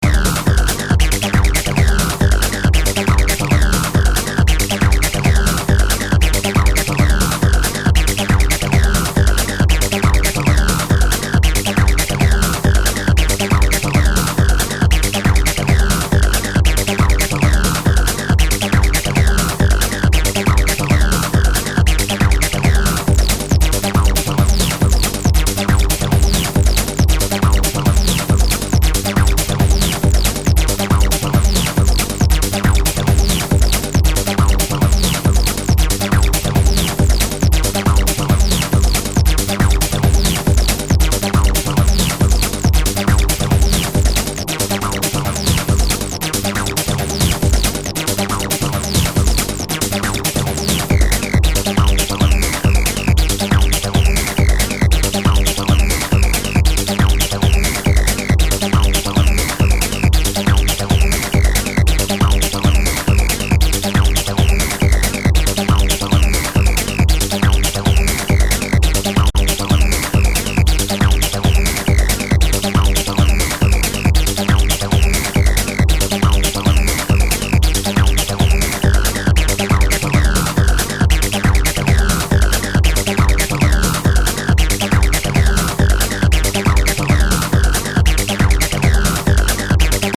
All about funk and smooth disco grooves.